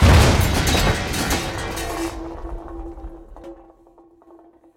planeCrash.ogg